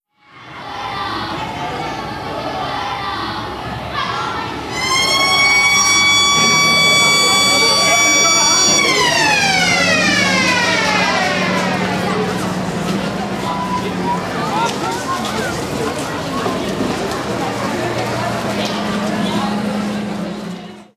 Salida de clase de los niños con la sirena
sirena
Sonidos: Gente
Sonidos: Acciones humanas
Sonidos: Voz humana